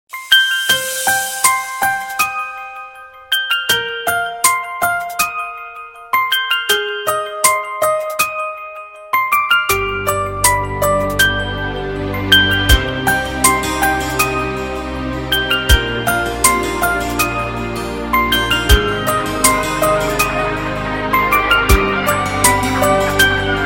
Arabisk Musik, Android, Ledsen musik, Musik